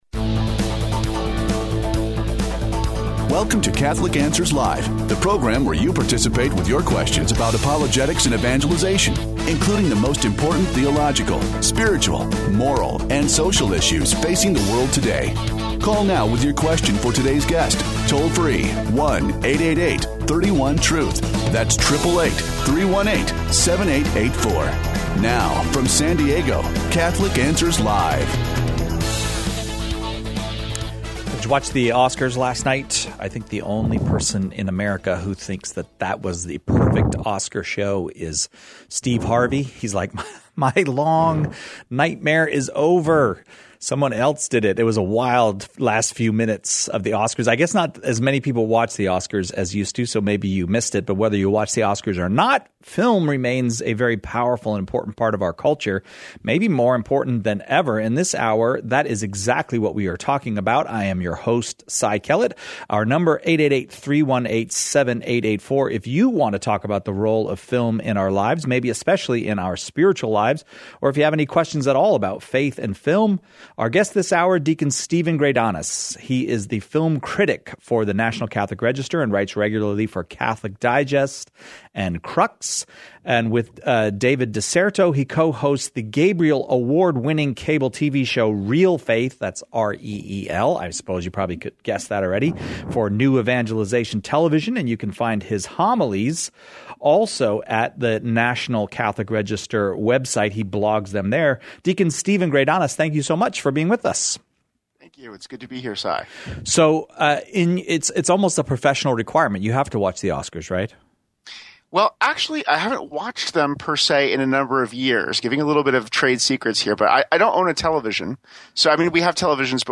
talks with callers about the state of film in today’s culture